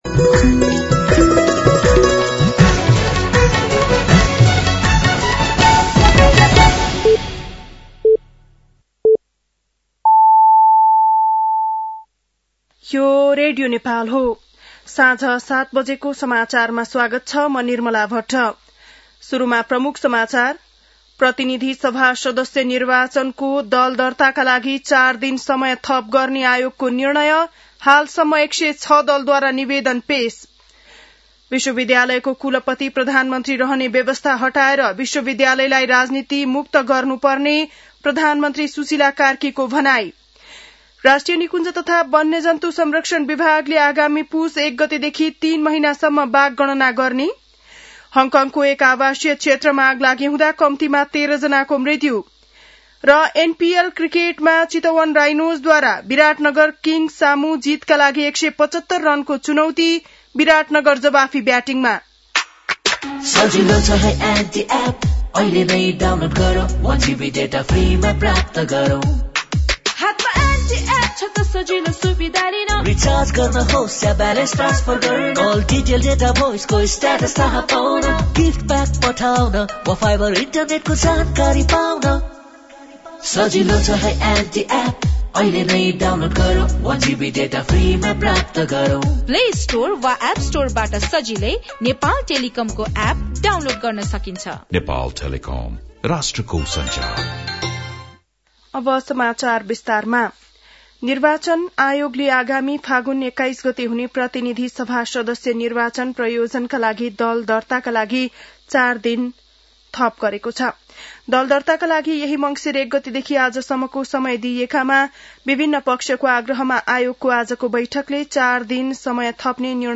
An online outlet of Nepal's national radio broadcaster
बेलुकी ७ बजेको नेपाली समाचार : १० मंसिर , २०८२